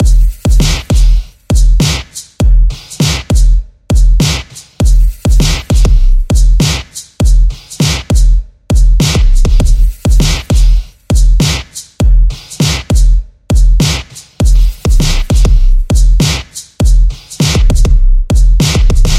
描述：速度：100bpm 一个要求的循环
Tag: 100 bpm Hip Hop Loops Drum Loops 3.24 MB wav Key : C